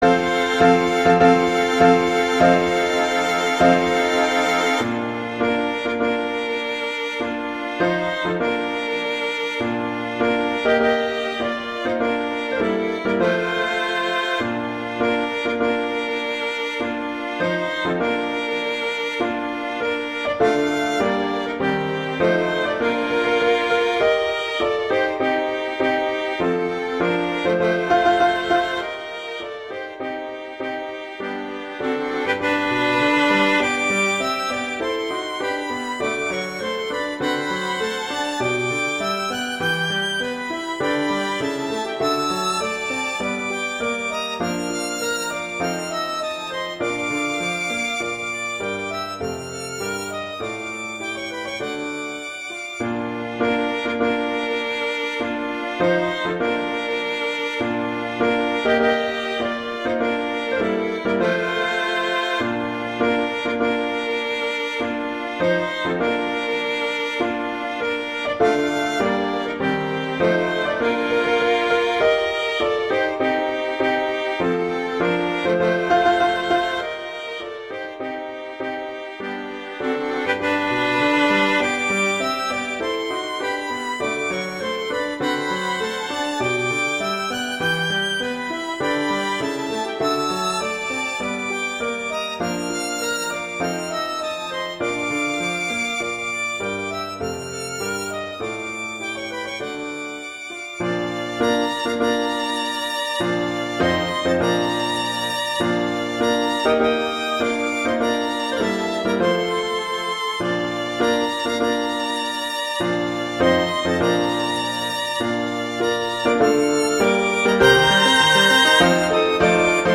Instrumentation: two violins & piano
arrangements for two violins and piano